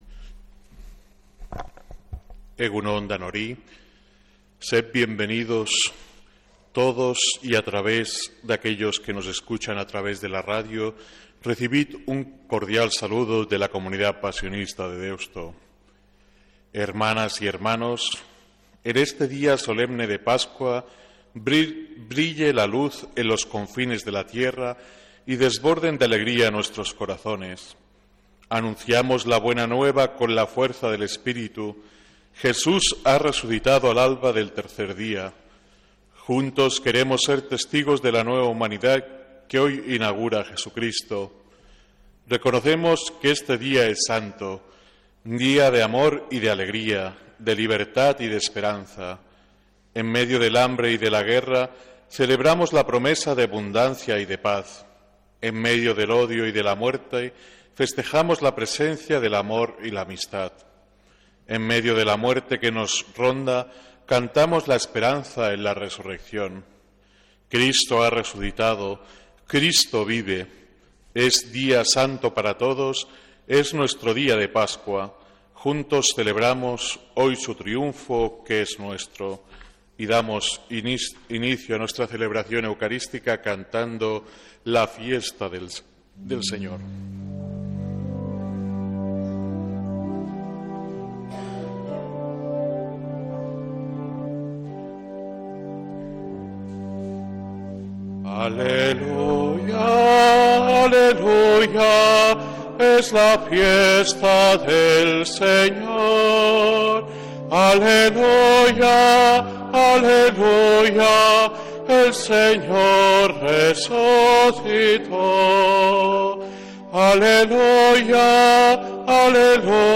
Santa Misa desde San Felicísimo en Deusto, domingo 5 de abril de 2026